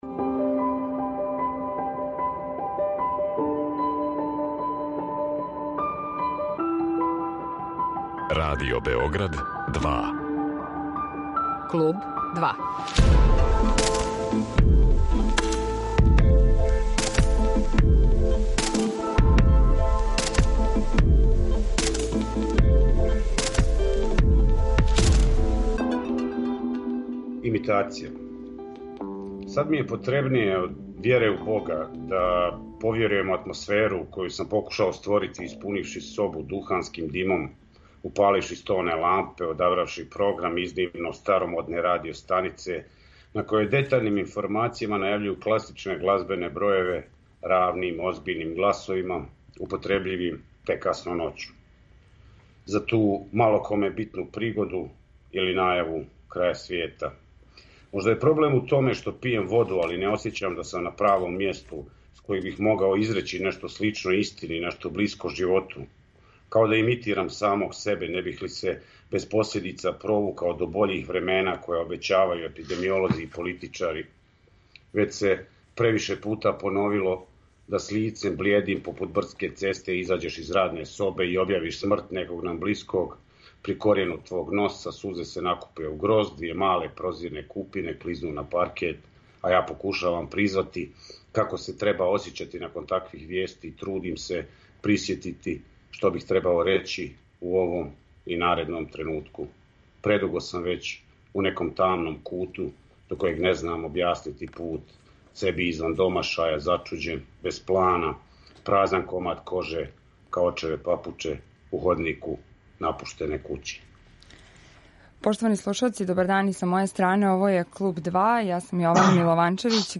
Разговараћемо о позицији уметника у свету, фигури одметника, неразумевању, слободи, потреби за лепотом, повлашћеним деловима дана, најзад и о искрености, успоменама, неким градовима и великим причама у уметности. Разговор води: